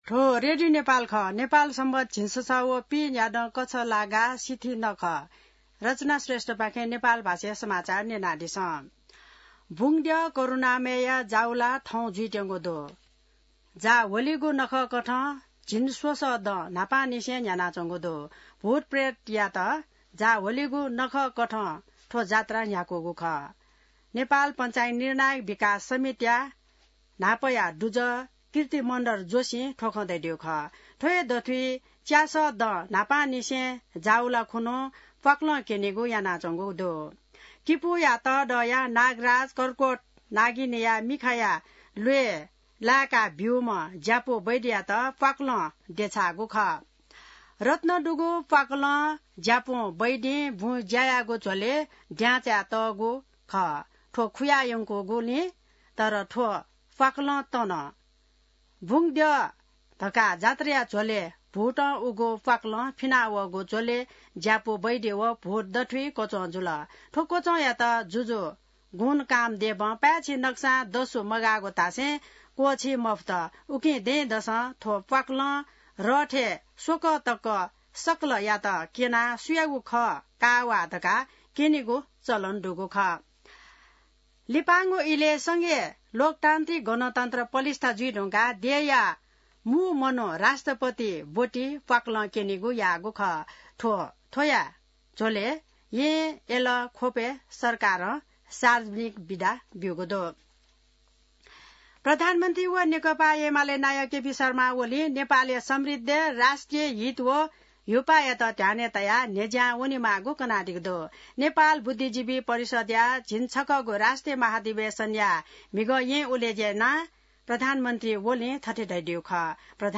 नेपाल भाषामा समाचार : १८ जेठ , २०८२